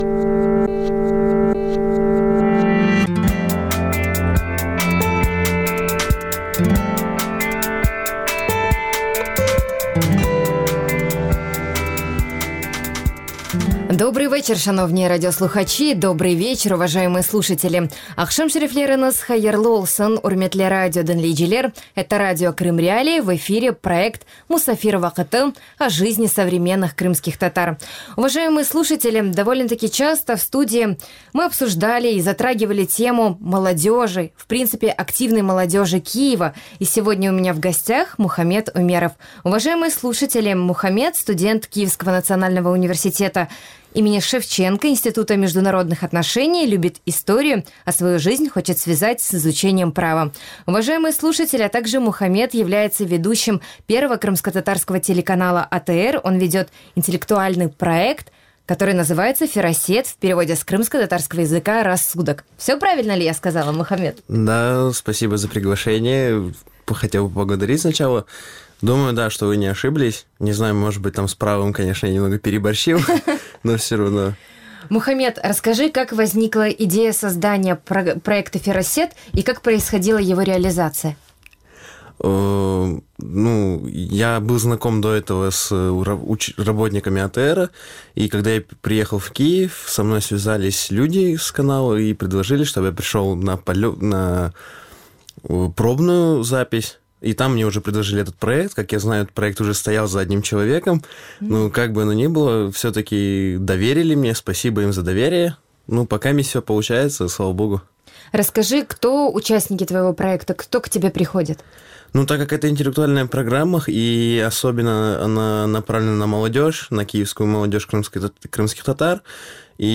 Програма звучить в ефірі Радіо Крим.Реалії. Час ефіру: 18:40 – 18:50.